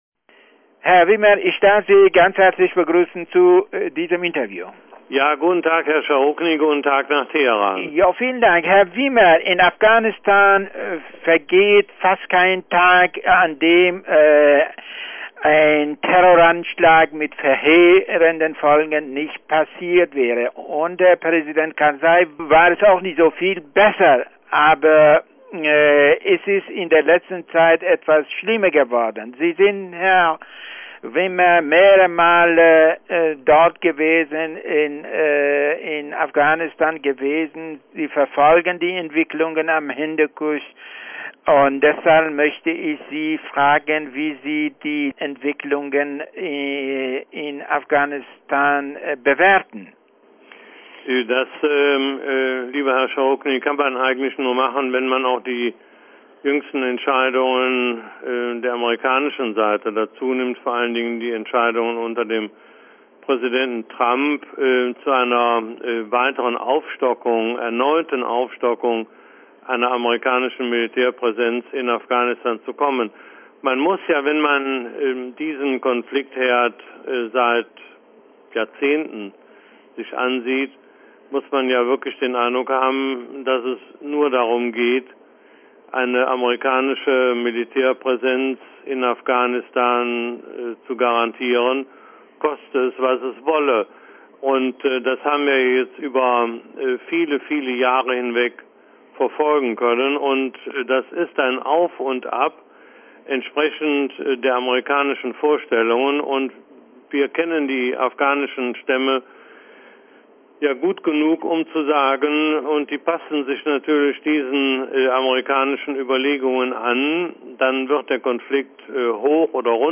Interview mit Willi Wimmer